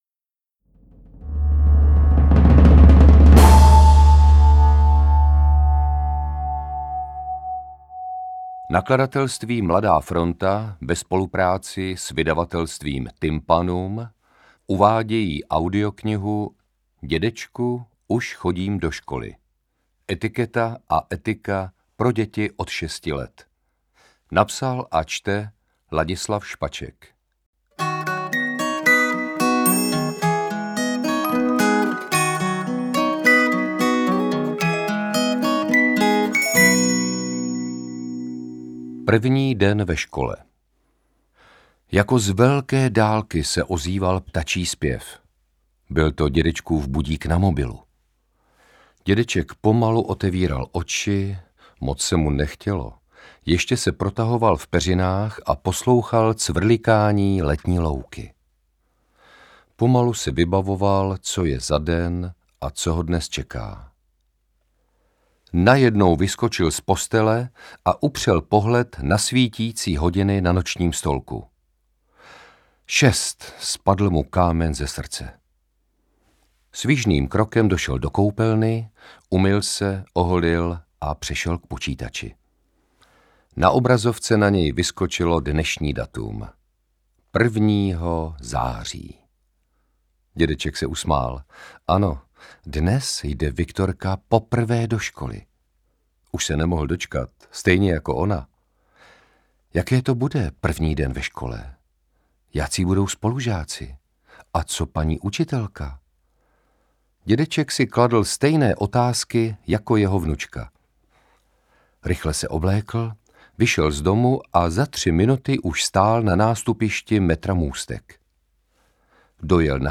Interpret:  Ladislav Špaček